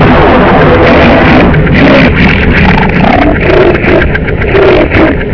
gunship_crash2.ogg